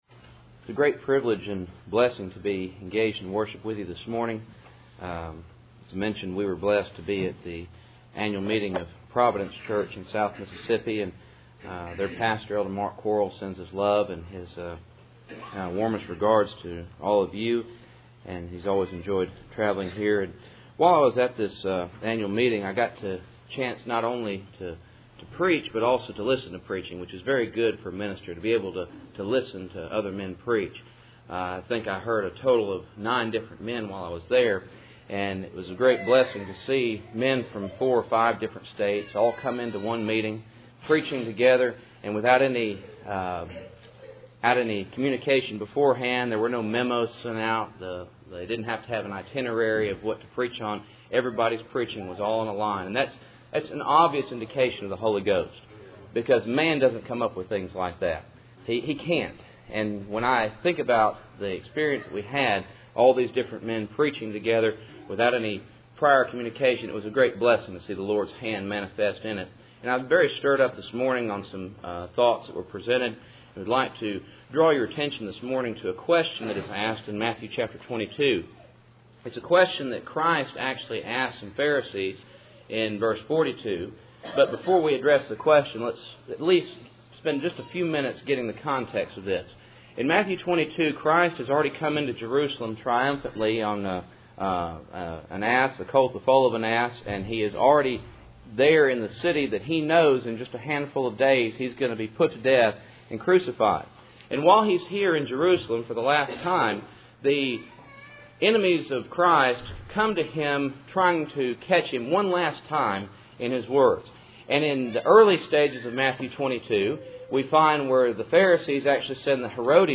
Cool Springs PBC Sunday Morning %todo_render% « Not According to the Flesh Part 2